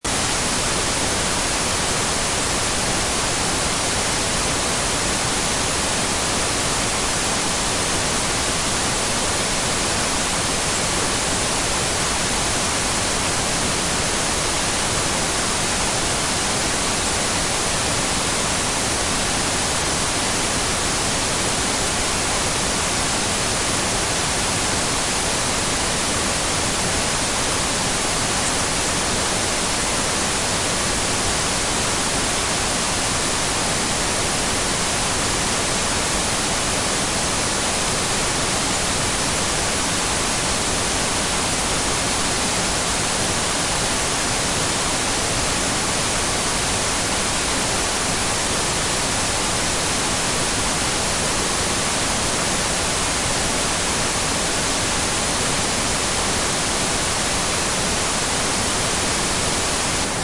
Download Tv Static sound effect for free.
Tv Static